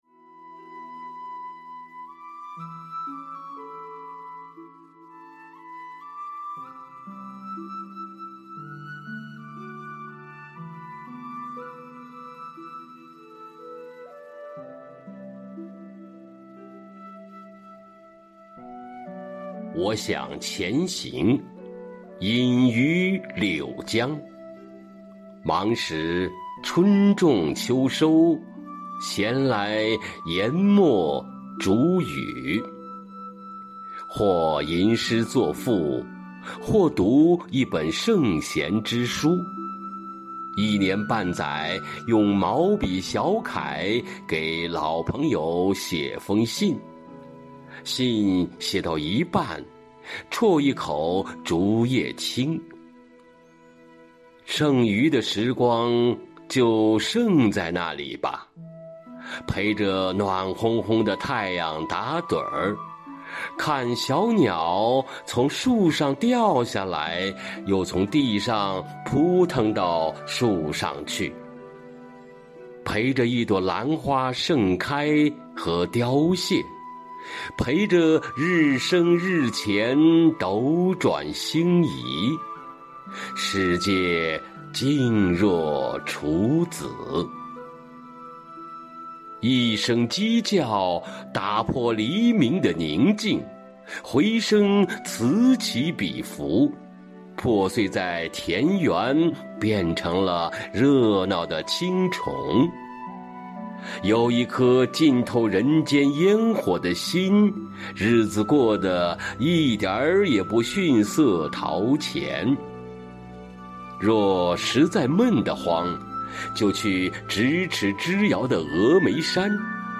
誦讀